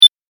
ボタン・システム （87件）